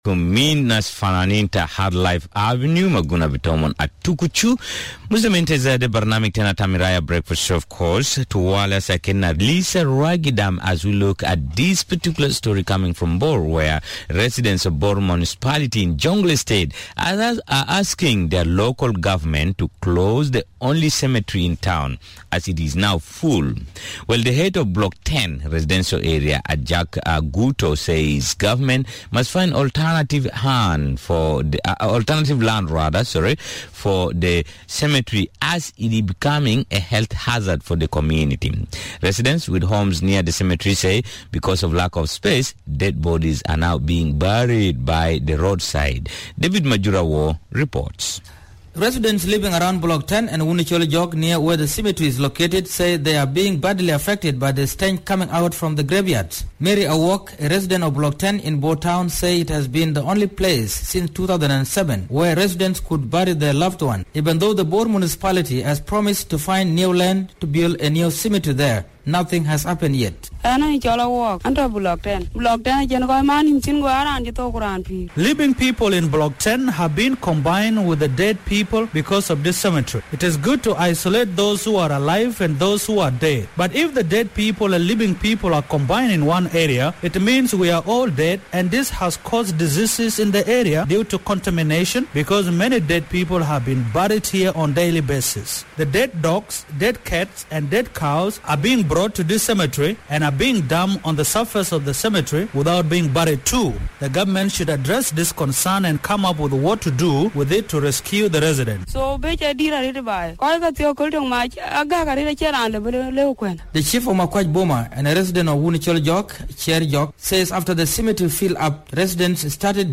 The Bor Cemetery Report